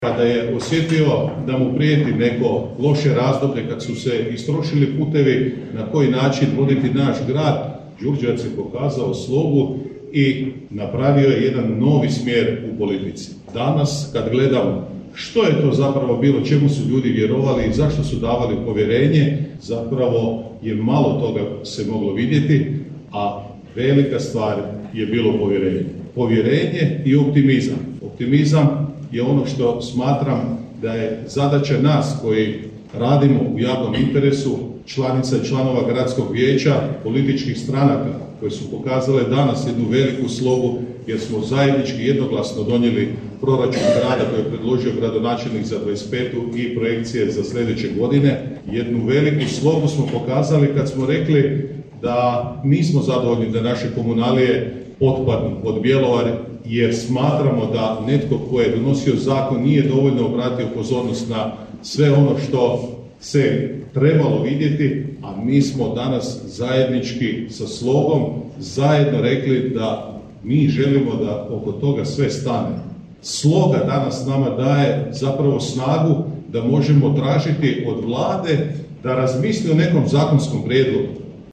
U Velikom salonu Muzeja Grada Đurđevca, gradonačelnik Hrvoje Janči i predsjednik Gradskoga vijeća i saborski zastupnik Željko Lacković već tradicionalno su organizirali prigodni domjenak za kraj još jedne uspješne godine.